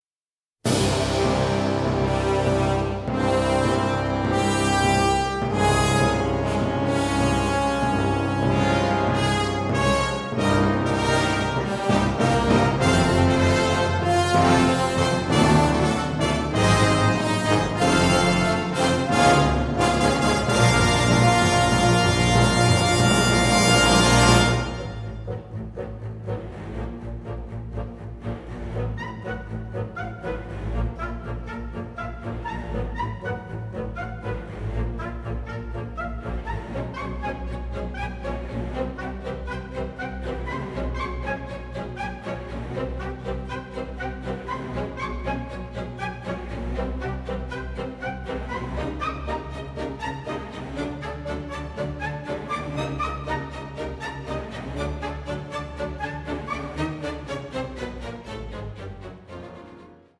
Recorded at CTS Studios in London